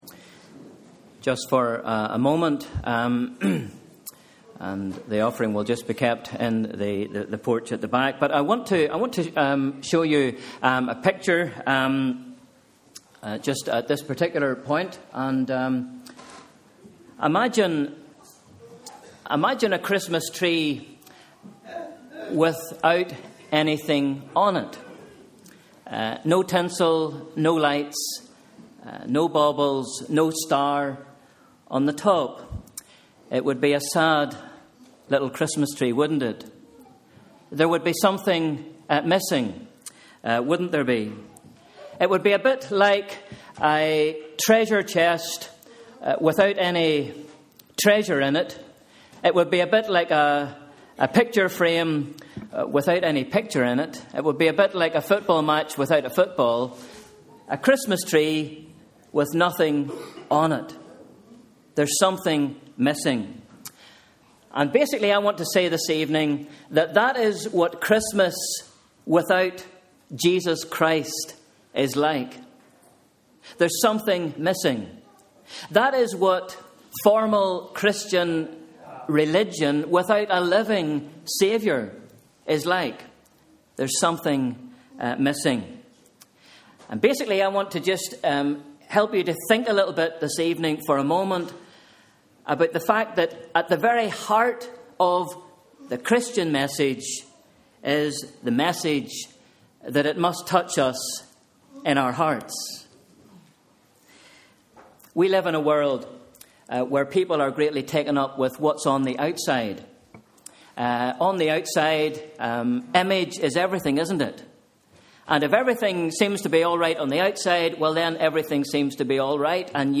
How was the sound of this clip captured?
Community Carol Service 2013